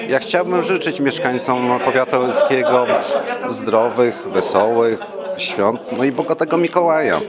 To już piękna świąteczna tradycja – wigilia w Radiu 5 Ełk.
Robert Dawidowski, wicestarosta powiatu ełckiego, życzy mieszkańcom regionu i powiatu spełnienia marzeń.